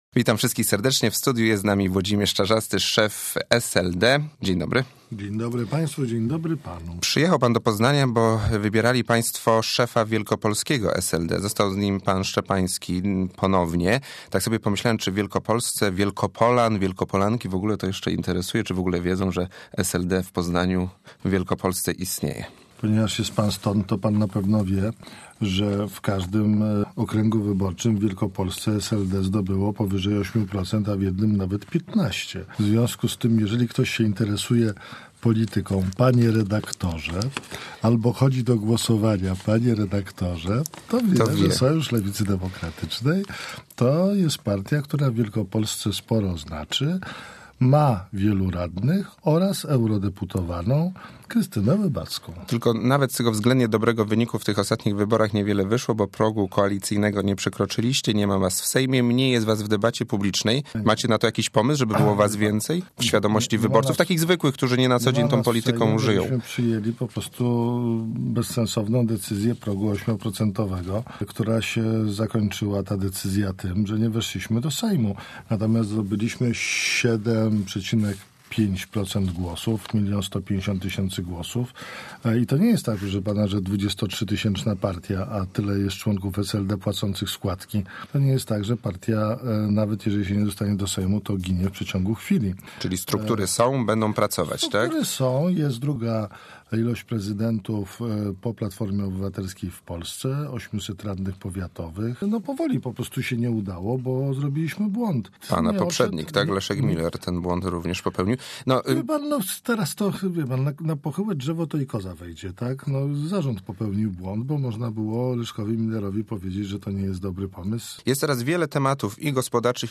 Taką deklarację złożył w porannej rozmowie Radia Merkury "Jest taka sprawa". szef SLD Włodzimierz Czarzasty.